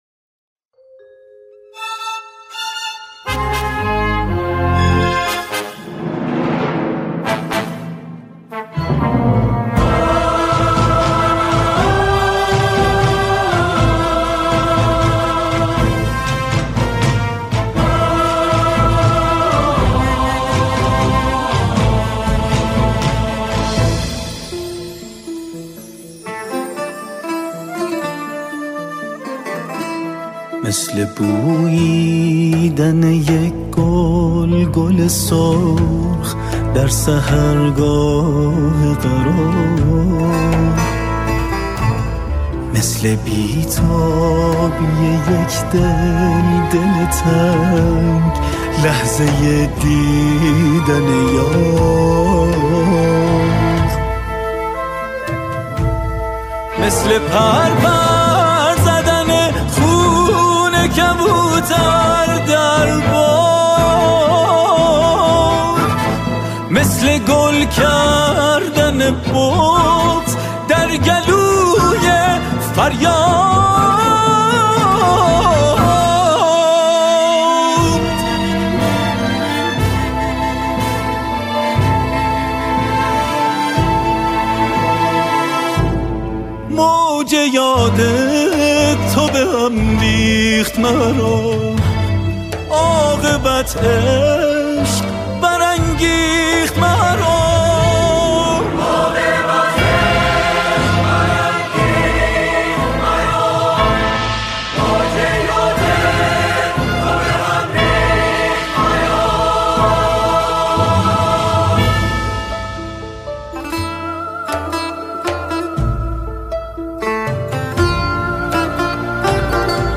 همخوانی شعری درباره “فلسطین”